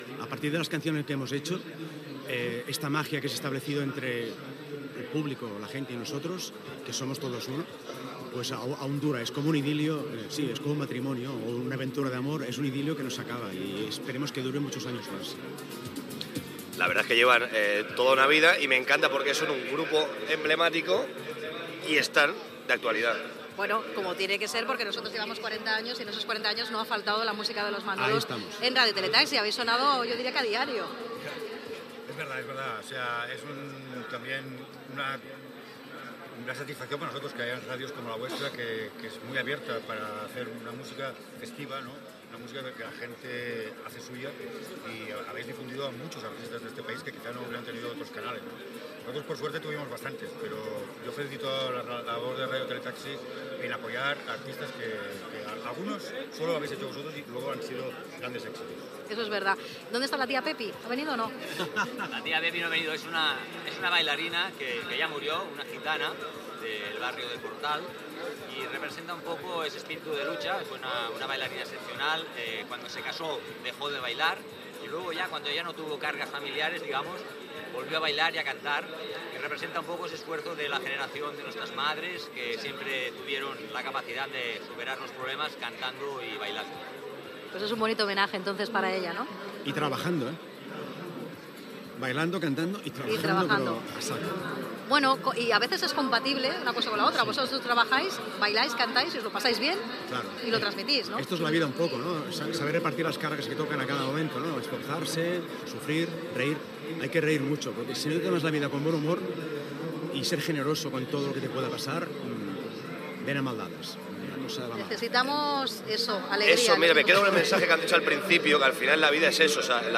Inici de la festa pels 40 anys de l'emissora des d'Illa Fantasia de Vilassar de Dalt: entrevista als germans Herrero de Los Manolos
poema dedicat a les víctimes de la pandèmia de la Covid-19 i actuació musical. Gènere radiofònic Musical